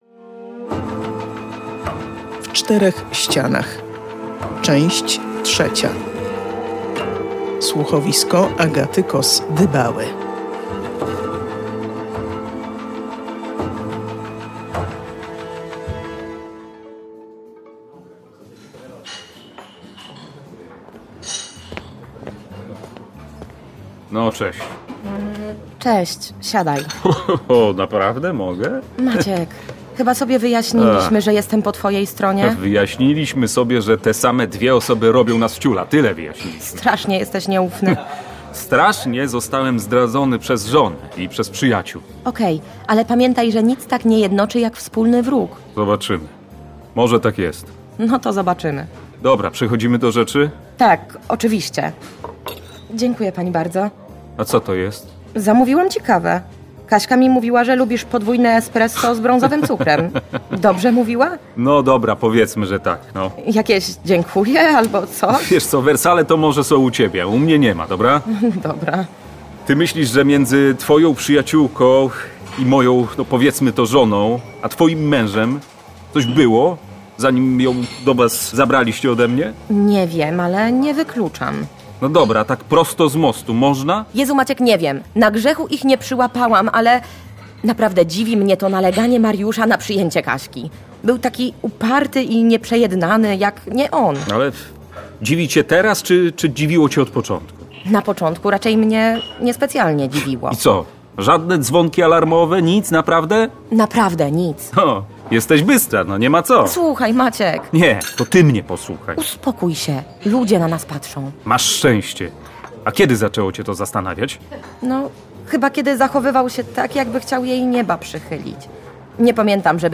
*Słuchowisko przeznaczone jest dla osób dorosłych.